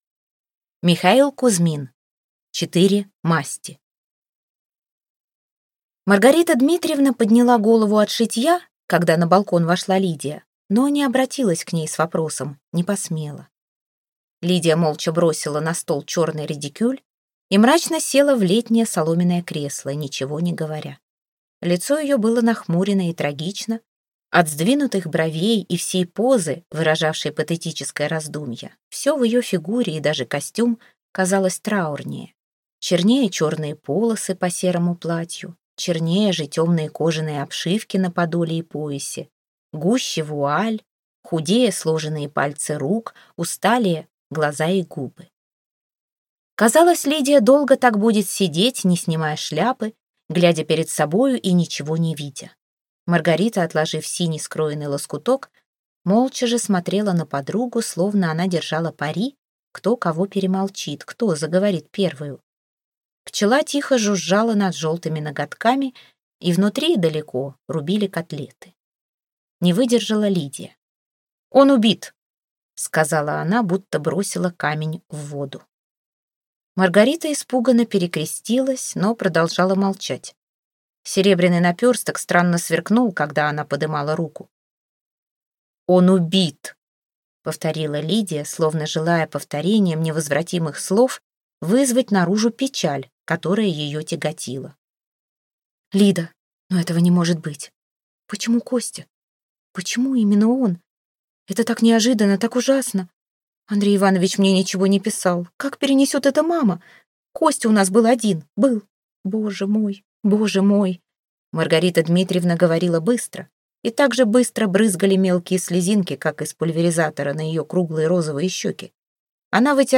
Аудиокнига Четыре масти. Шесть невест короля Жильберта | Библиотека аудиокниг